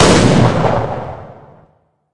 Media:shelly_ulti_01.wav 技能音效 super 高手形态发射超级霰弹音效